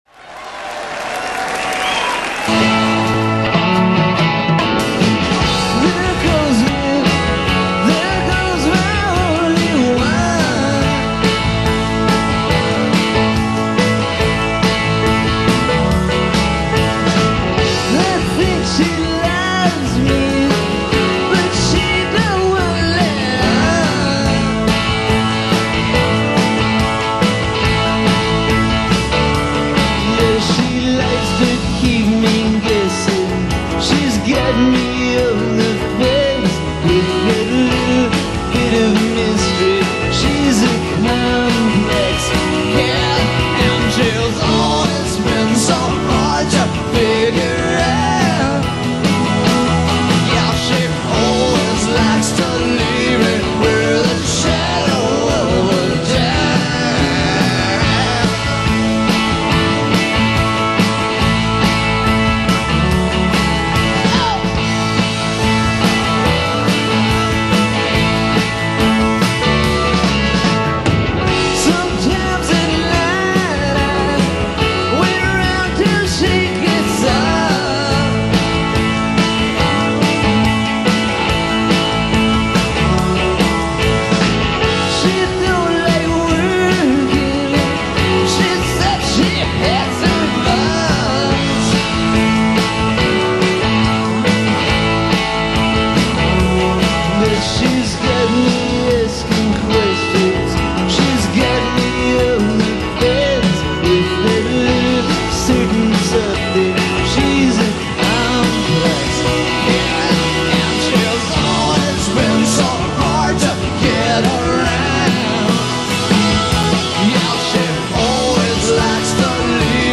In Concert.
Some Heartland Rock this morning